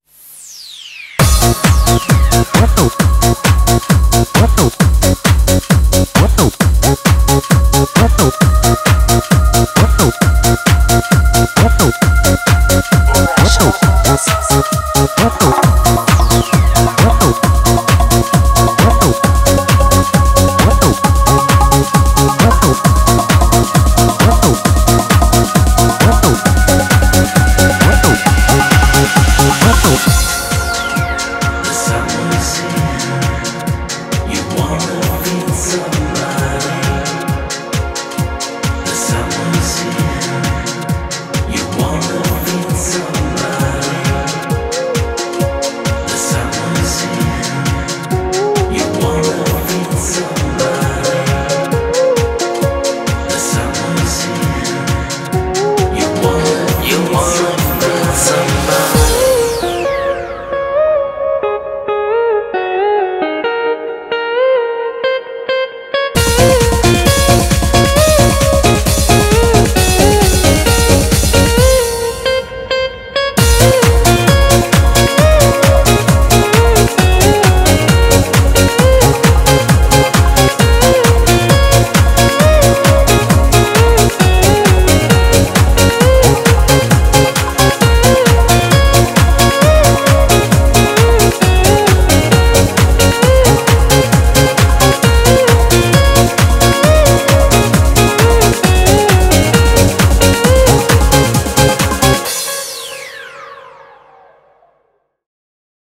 BPM133
Audio QualityPerfect (High Quality)
trance track